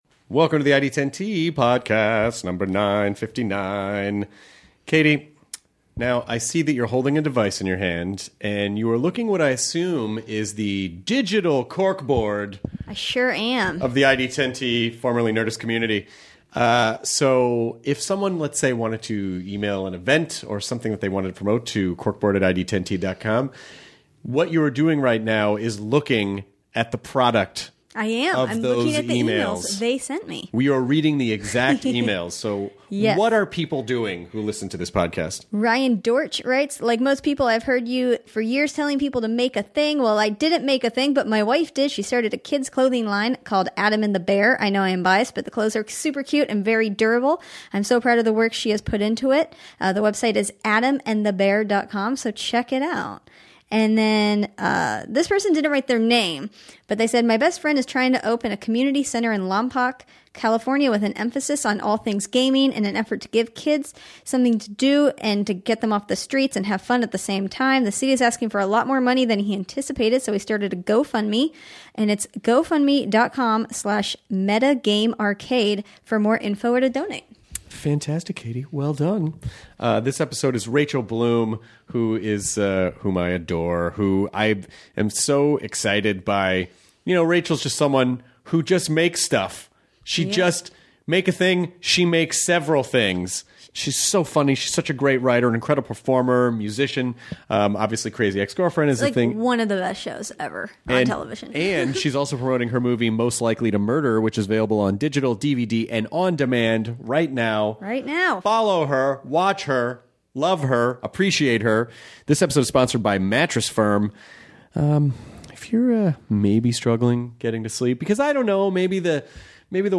Rachel Bloom (Crazy Ex-Girlfriend) chats with Chris and Jonah about their experiences in improv, what it is like being a woman in comedy and dealing with anxiety while growing up. Rachel also talks about ending Crazy Ex-Girlfriend with season 4 and making her new movie Most Likely To Murder!